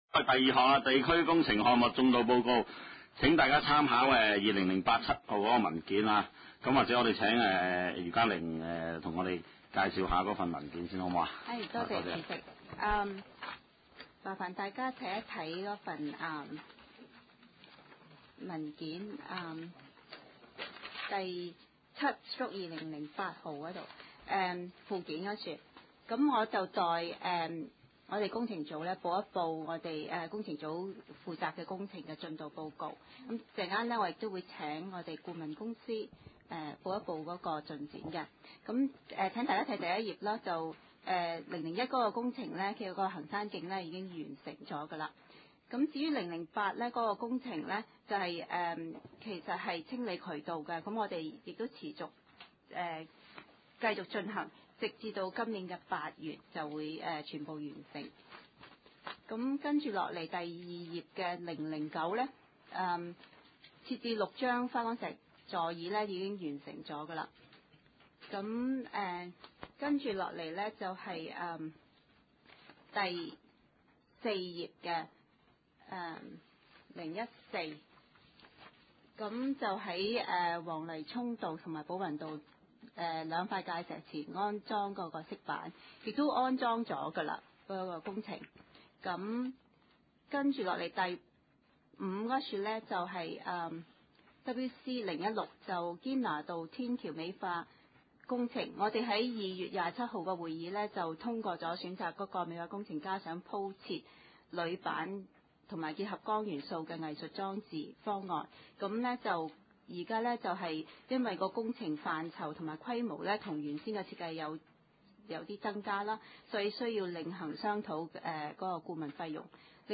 地區工程及設施管理委員會第三次會議
灣仔民政事務處區議會會議室